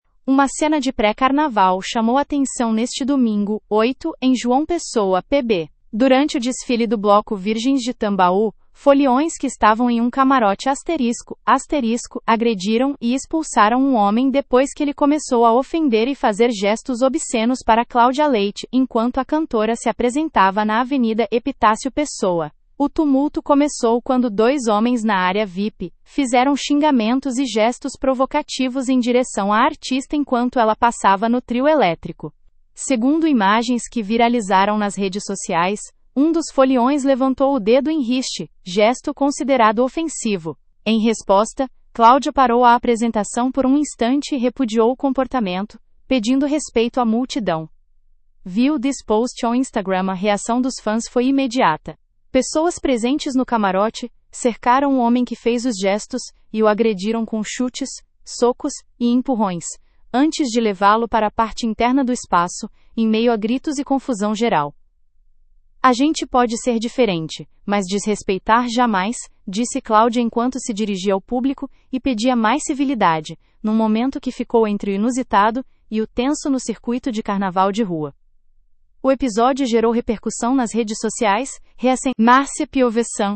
Em resposta, Claudia parou a apresentação por um instante e repudiou o comportamento, pedindo respeito à multidão.
Pessoas presentes no camarote cercaram o homem que fez os gestos e o agrediram com chutes, socos e empurrões, antes de levá-lo para a parte interna do espaço, em meio a gritos e confusão geral.